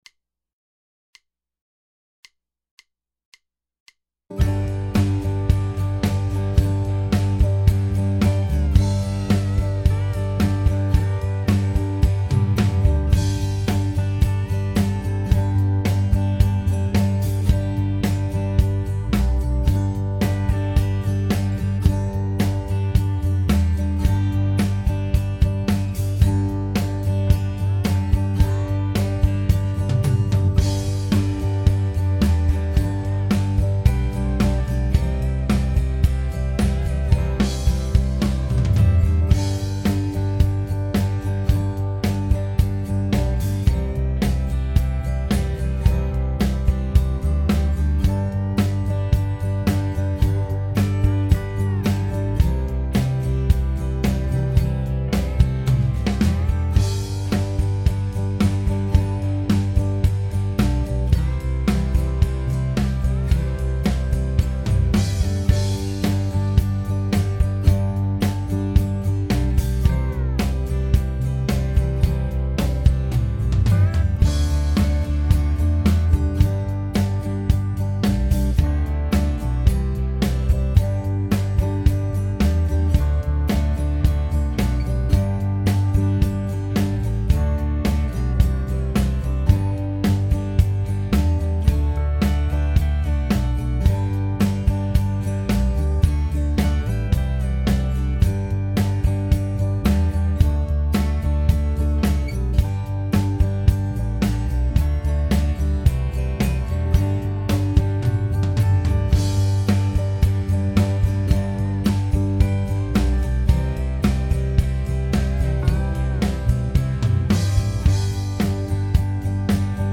billy-stay-practice-track.mp3